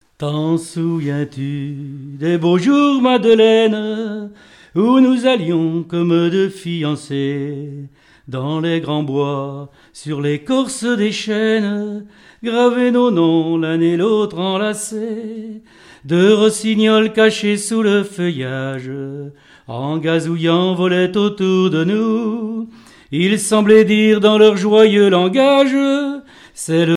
Saint-Georges-de-Montaigu
Genre strophique
répertoire de chansons, et d'airs à danser
Pièce musicale inédite